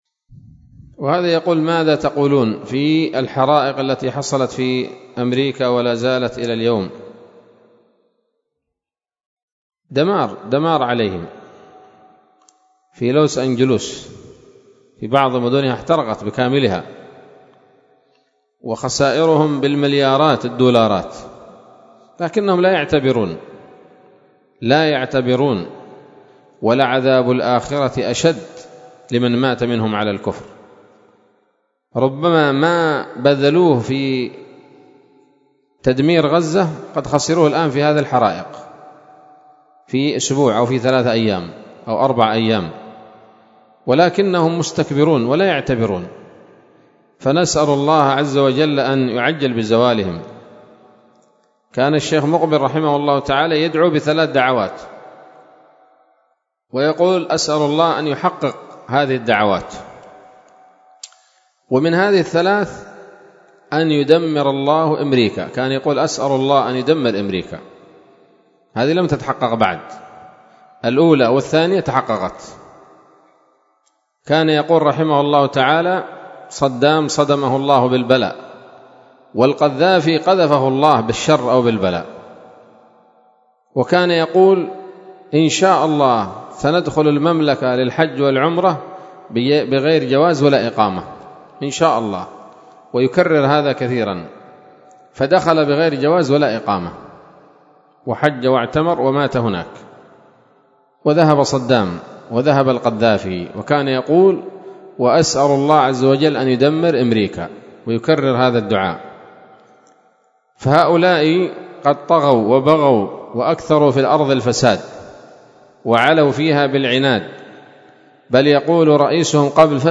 كلمة قيمة بعنوان: (( كلمة حول ما حصل من حرائق في بلاد أمريكا )) ليلة الإثنين 13 شهر رجب 1446هـ، بدار الحديث السلفية بصلاح الدين